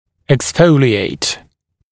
[eks’fəulɪeɪt][экс’фоулиэйт]выпадать (о молочных зубах)